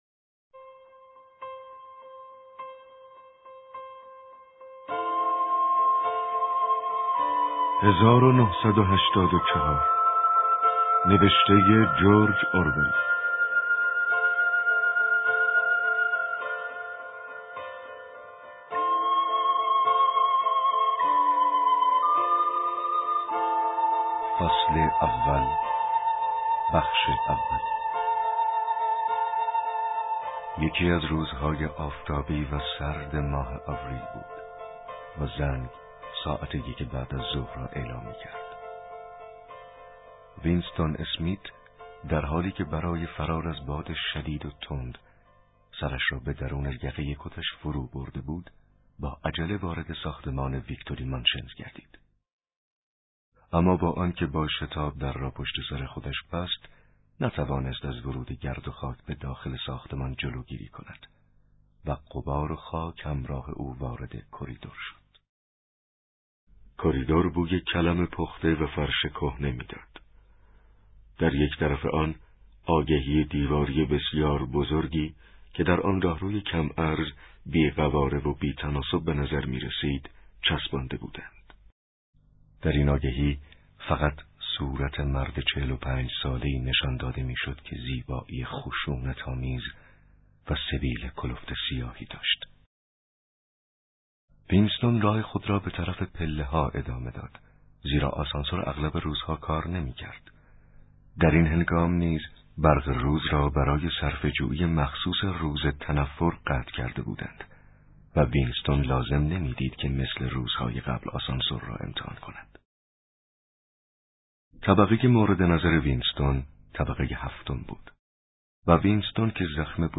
کتاب صوتی « 1984»